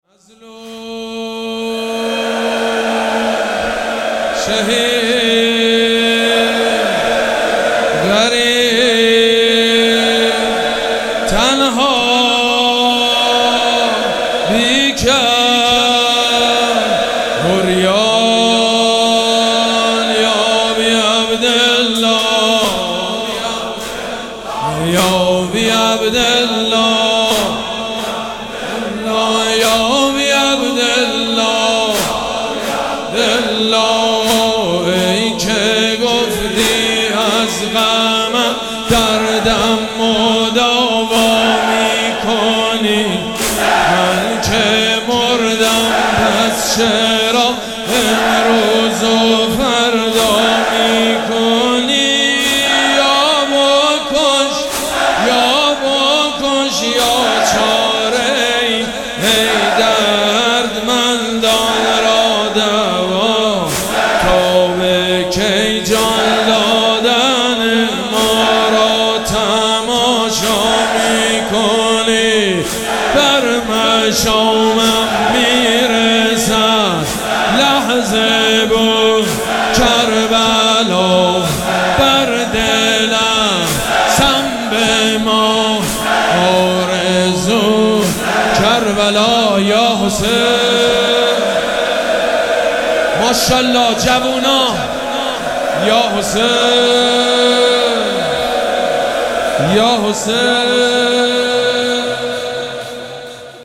مراسم عزاداری شب دوم محرم الحرام ۱۴۴۷
مداح
حاج سید مجید بنی فاطمه